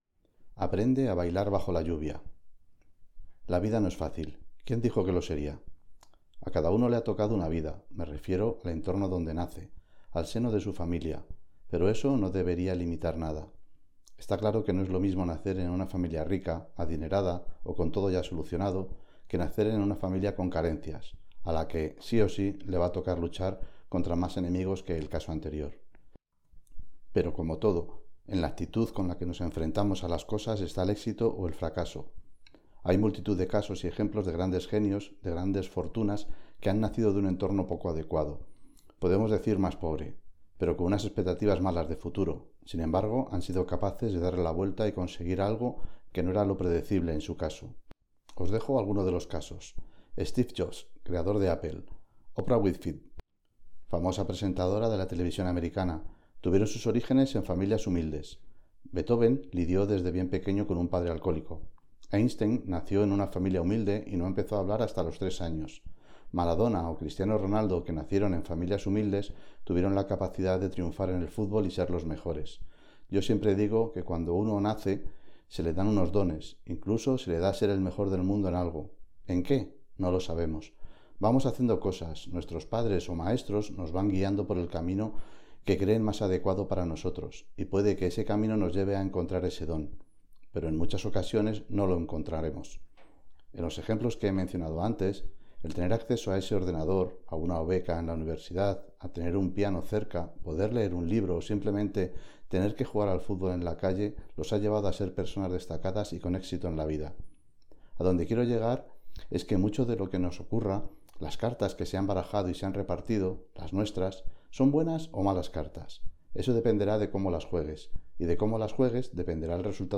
Audiolibro - Todo va a salir bien - APRENDE A BAILAR BAJO LA LLUVIA - Asociación Esclerosis Múltiple